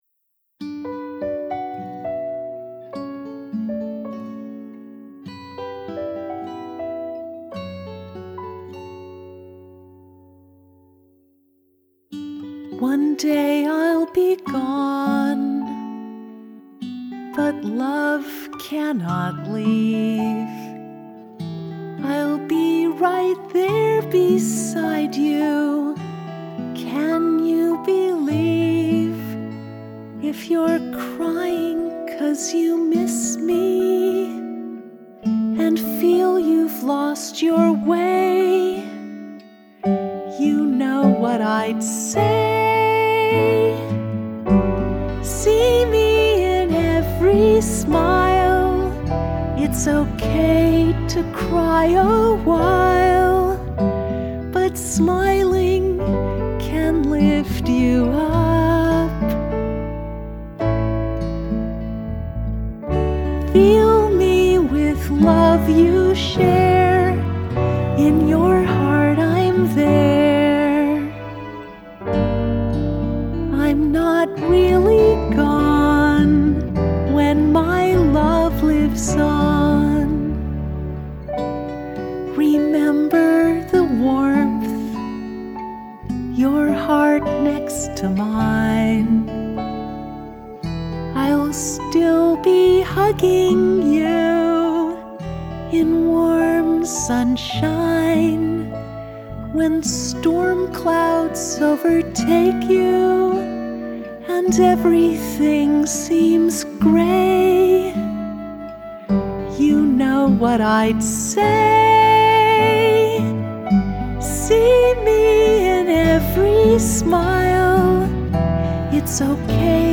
in-every-smile-vocal-mix-9-26.mp3